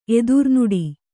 ♪ edurnuḍi